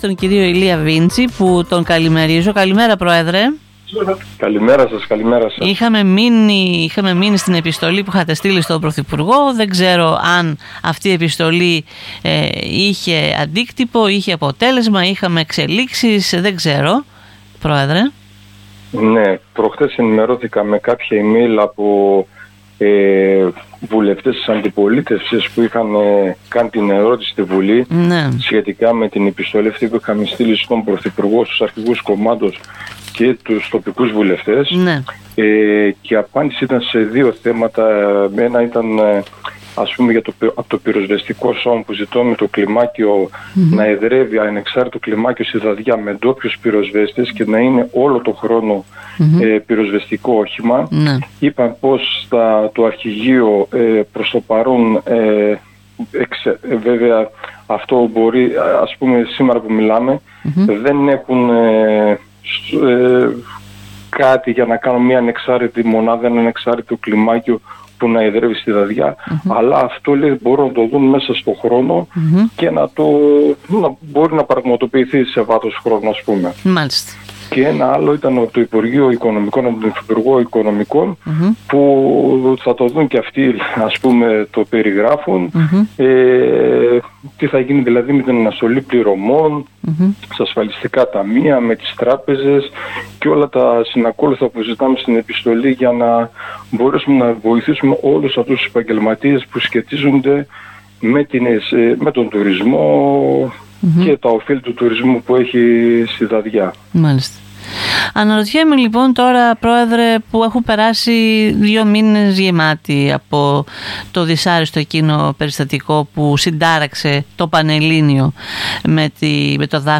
Δυο μήνες μετά το τραγικό συμβάν για την περιοχή, η κατάσταση που επικρατεί είναι υποτονική σύμφωνα με δηλώσεις του προέδρου Ηλία Βίντζη στην ΕΡΤ Ορεστιάδας, ενώ δεν υπάρχει καμία επίσημη δέσμευση στα αιτήματα τους.